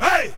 SouthSide Chant (35)(1).wav